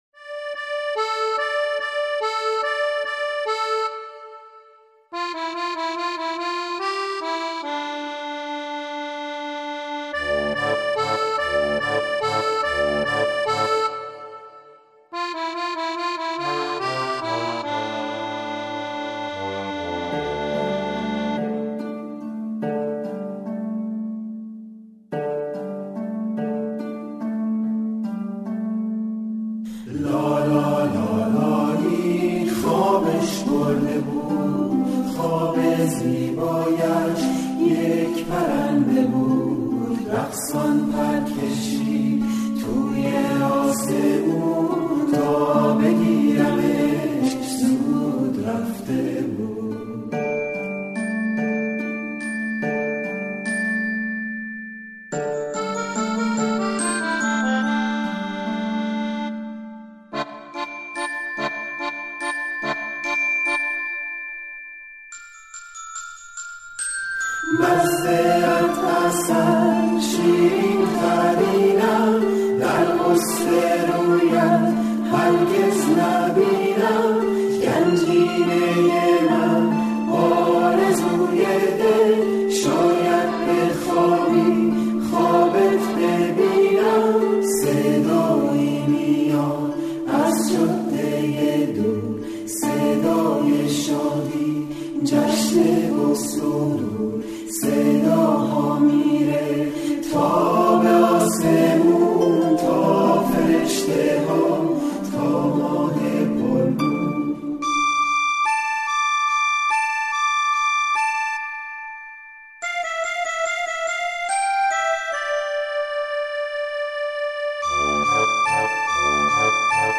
• لالایی آهنگ لالایی